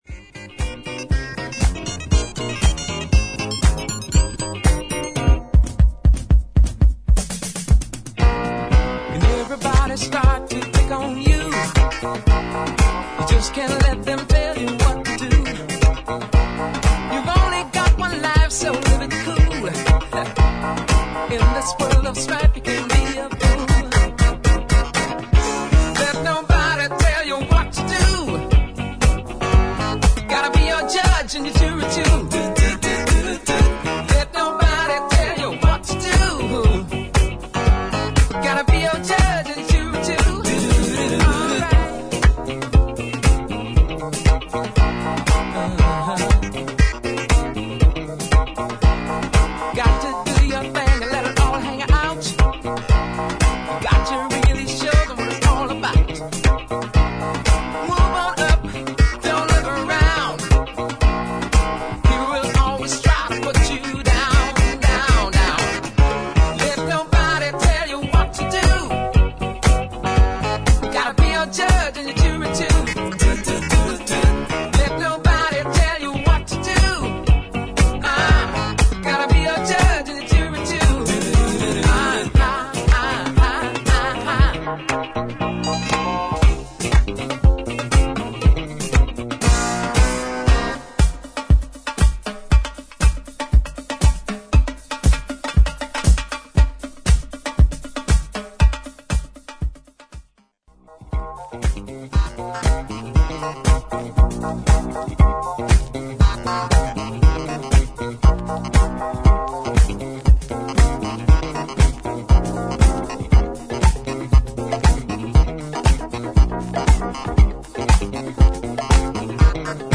FORMAT : 12"